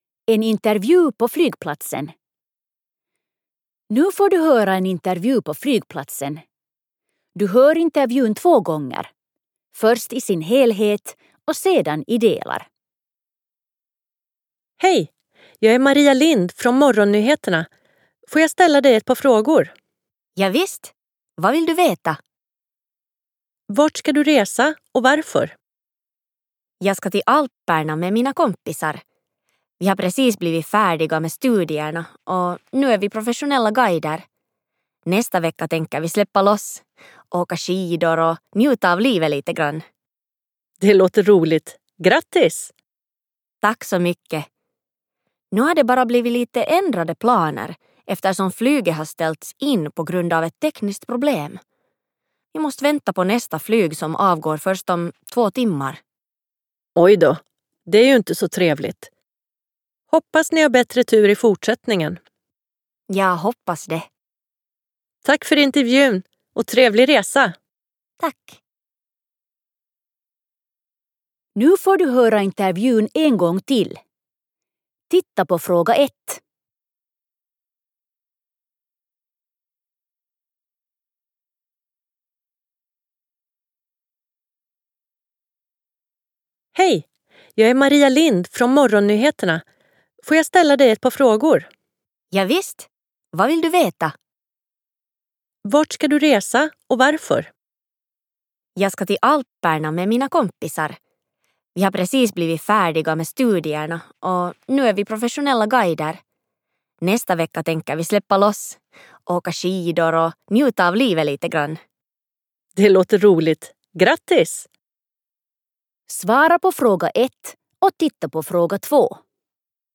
19_Resor_Intervu_flygplatsen_1.mp3